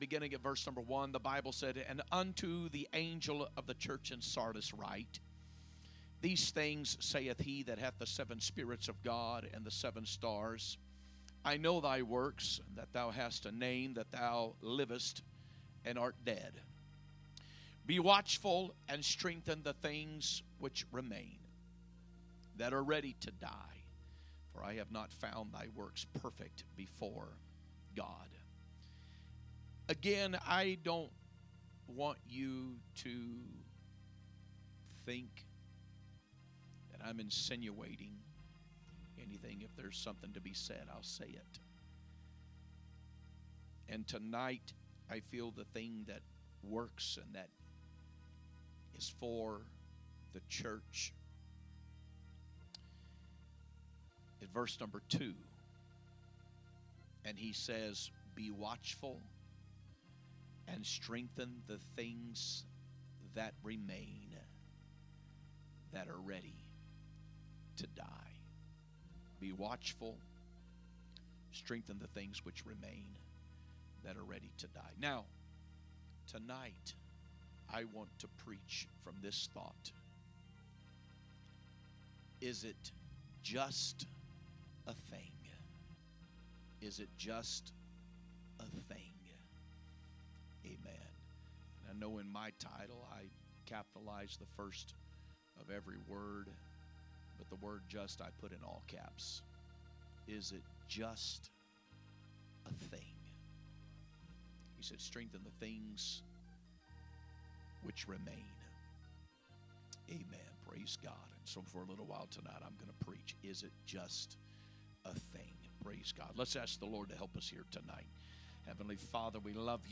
Wednesday Service